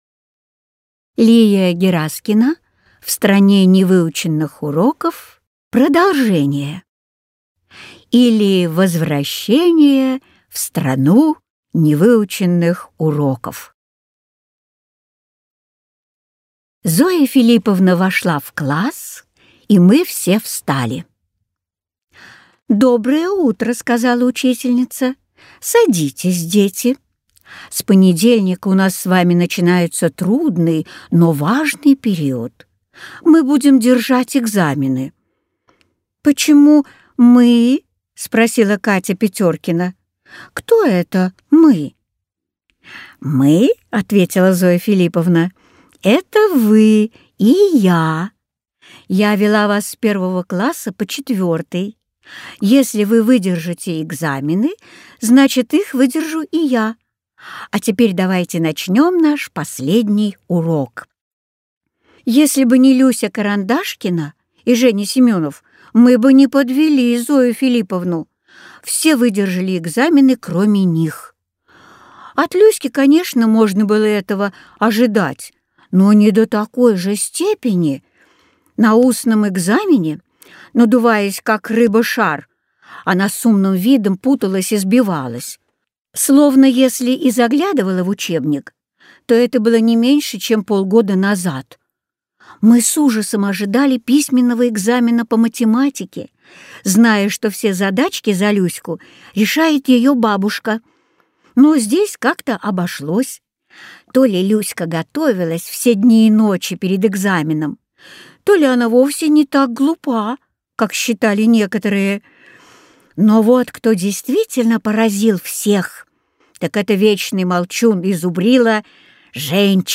Аудиокнига В Стране невыученных уроков. Продолжение | Библиотека аудиокниг